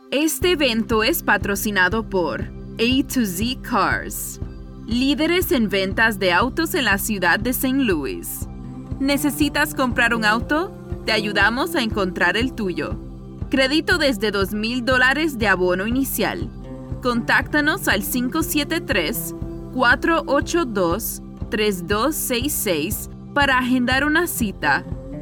Promotions
Ma voix combine naturellement chaleur, clarté et énergie dynamique, ce qui en fait un choix parfait pour les projets qui nécessitent un son relatable et professionnel.